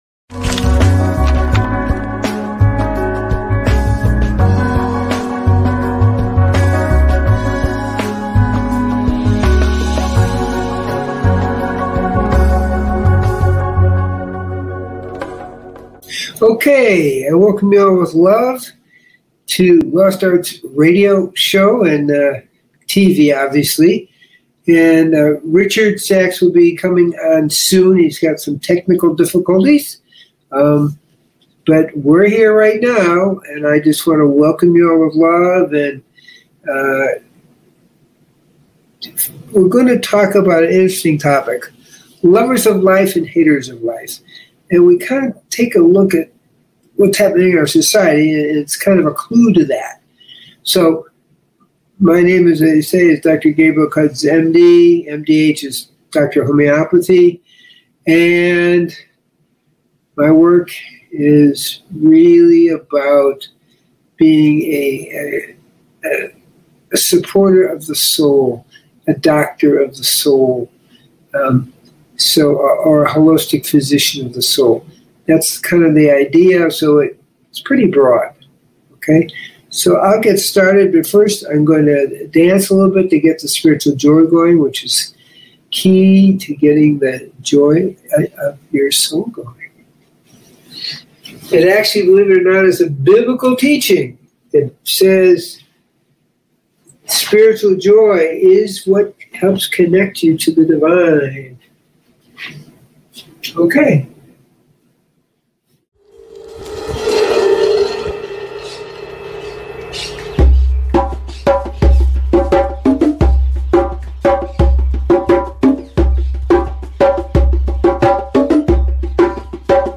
Lovers Of Life & Haters Of Life - Dialogs